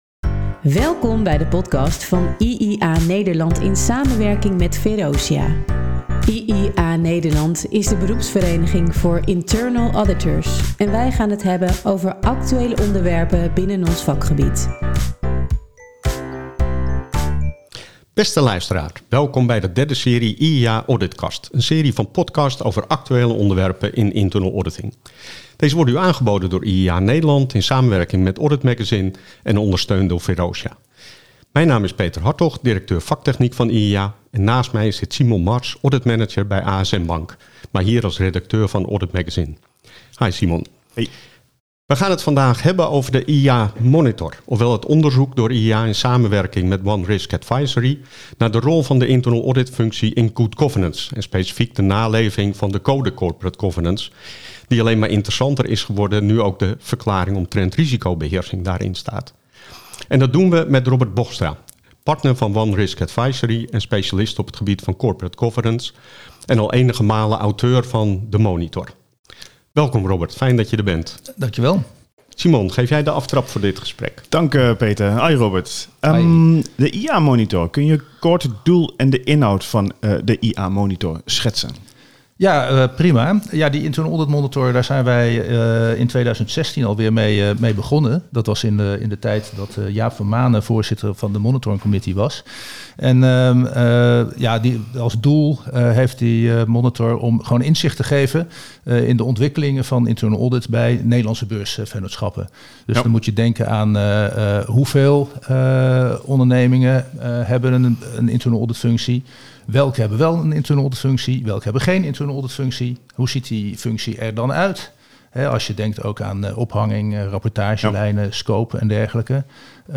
gaan hierover in gesprek